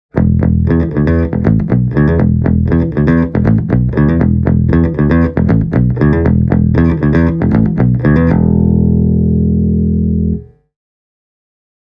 A punkish bit of plectrum-driven anger can be dialled in just as quickly on the EBS-stack:
Plectrum-Rock